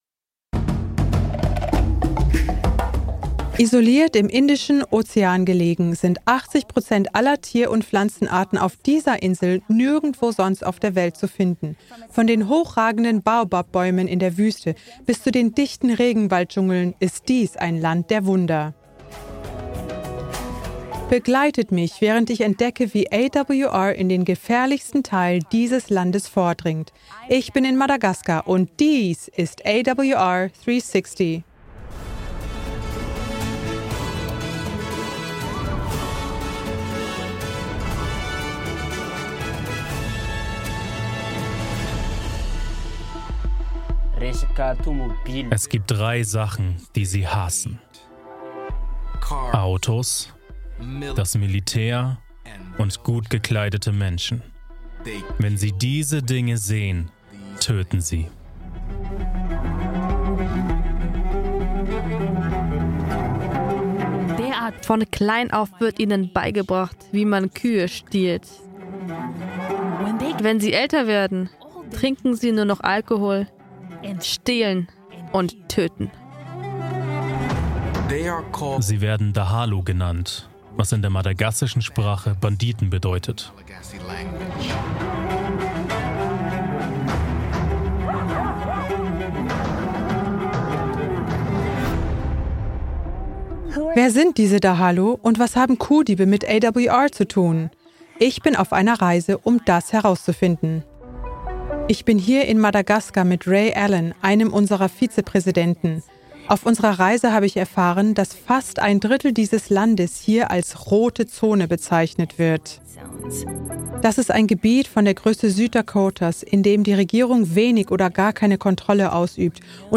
Kategorie Zeugnis